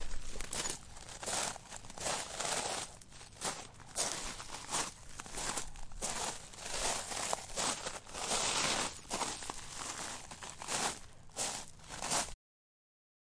农场氛围 " 砾石上的脚步声
描述：走在石渣的脚步户外在夏天。
Tag: 脚步 碎石 户外 步骤 一步 脚步 农场 现场记录 散步 夏天